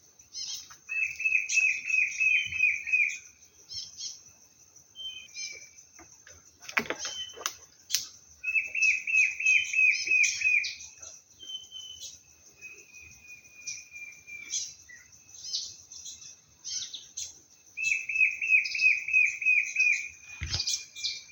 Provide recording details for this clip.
Province / Department: Misiones Location or protected area: Santa Ana Condition: Wild Certainty: Recorded vocal